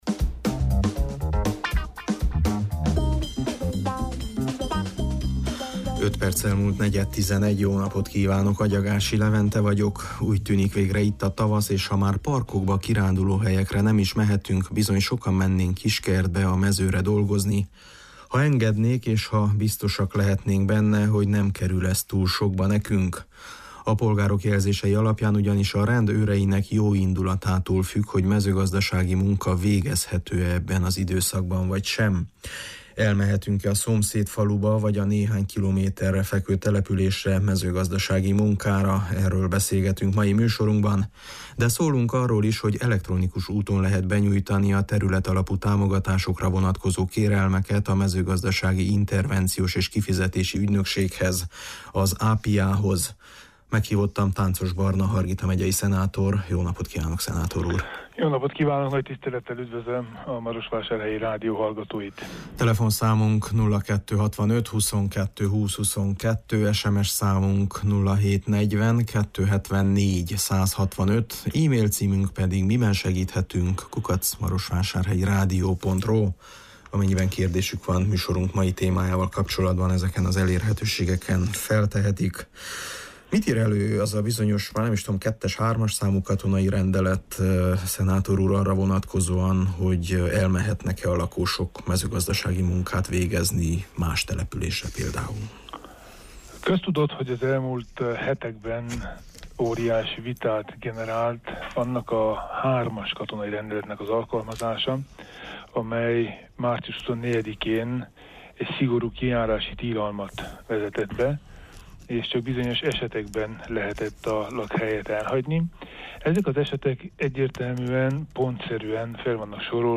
Tánczos Barna Miben segíthetünk? című műsorunkban további hasznos információkkal szolgált azok számára, akiknek különböző mezőgazdasági munkálatokat kell elvégezniük: